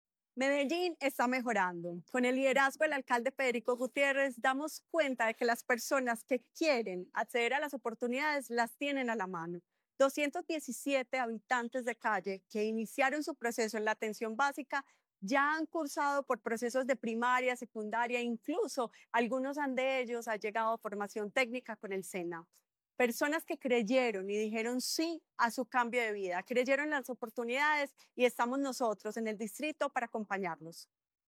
Declaraciones de la secretaria de Inclusión Social y Familia, Sandra Sánchez.
Declaraciones-de-la-secretaria-de-Inclusion-Social-y-Familia-Sandra-Sanchez..mp3